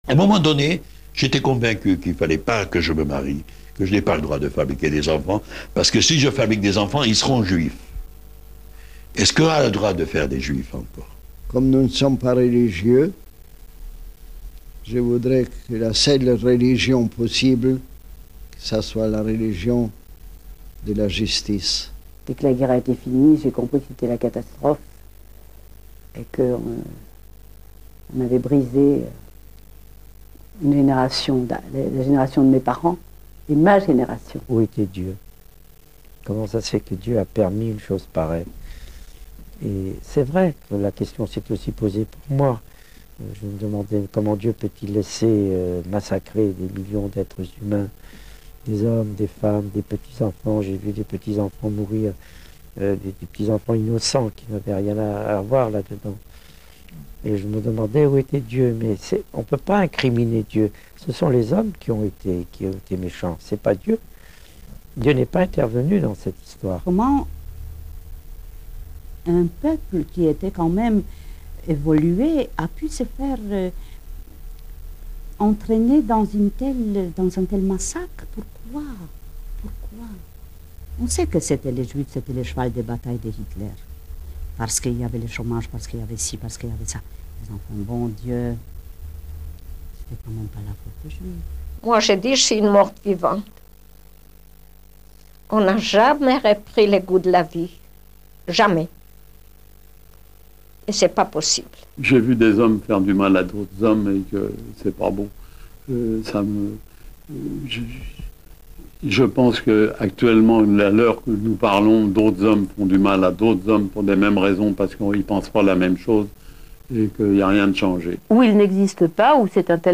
Voici donc quelques témoignages de personnes qui ont subi cette épreuve et vous remarquerez que certaines croient en Dieu et d'autres pas.